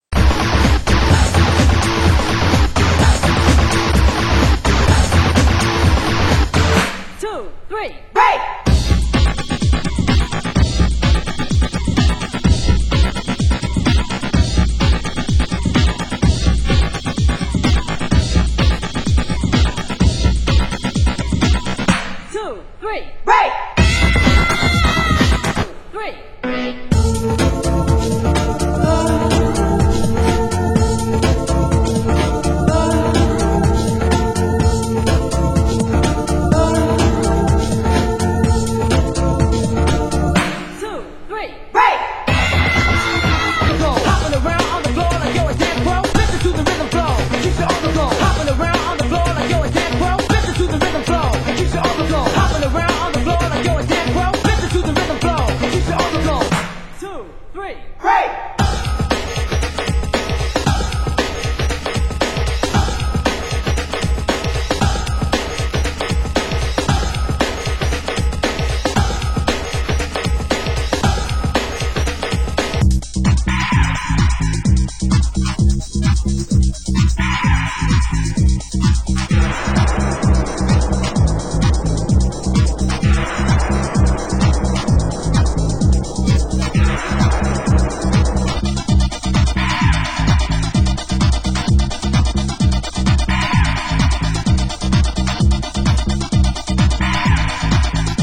Genre: Euro Rave (1990-92)